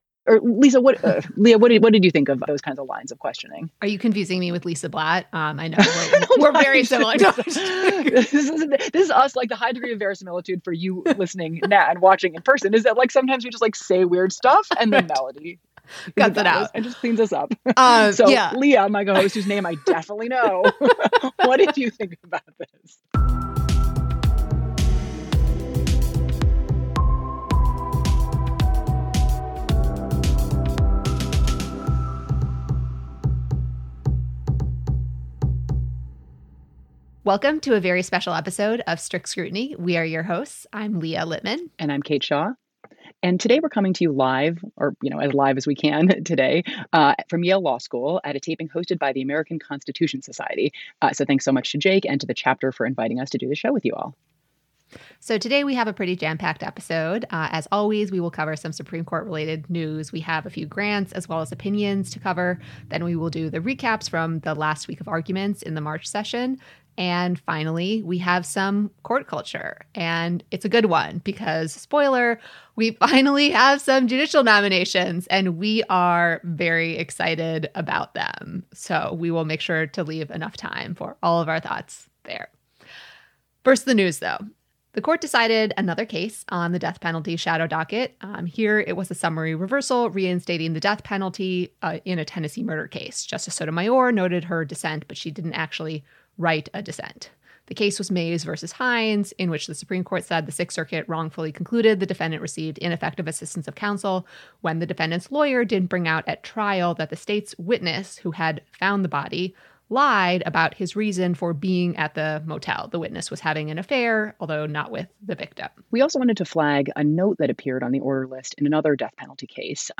“Live” from Yale Law’s ACS chapter